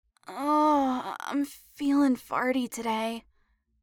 farty2.mp3